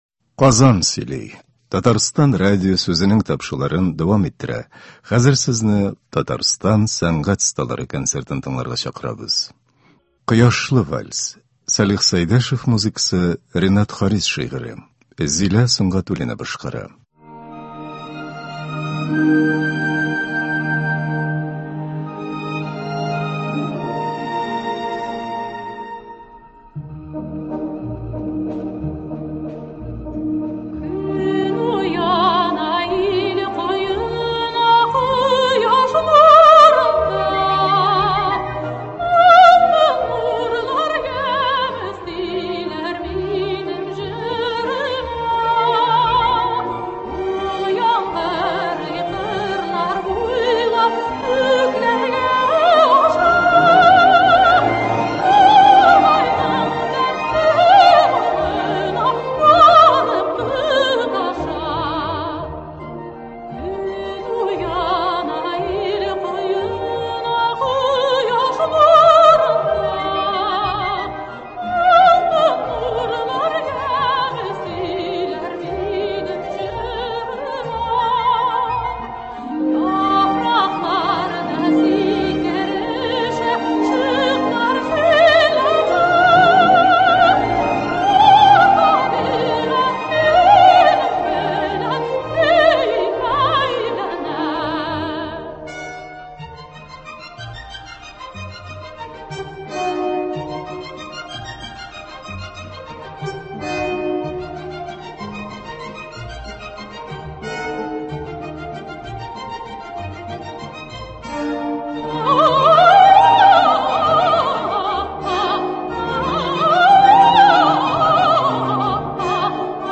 Башкалабызга багышланган җырлар. Сәнгать осталары концерты.